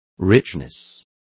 Complete with pronunciation of the translation of richness.